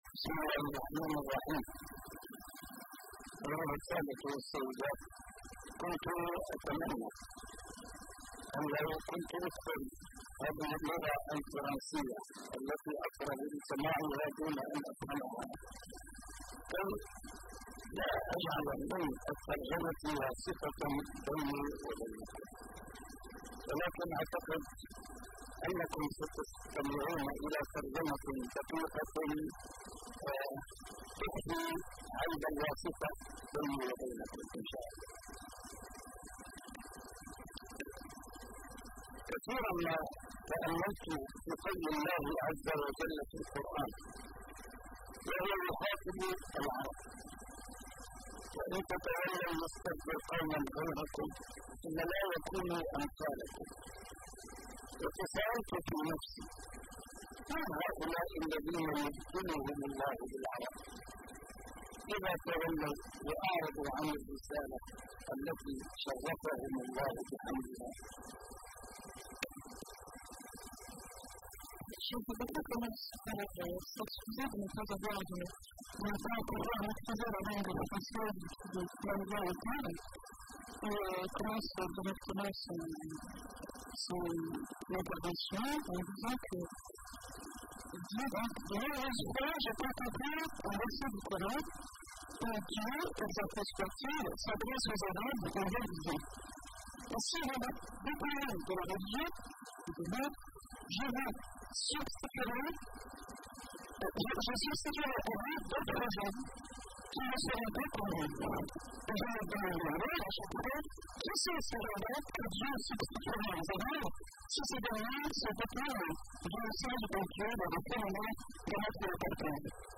A MARTYR SCHOLAR: IMAM MUHAMMAD SAEED RAMADAN AL-BOUTI - الدروس العلمية - محاضرات متفرقة في مناسبات مختلفة - أوروبا من التقنية إلى الروحانية...مشكلة الجسر المقطوع | محاضرة في فرنسا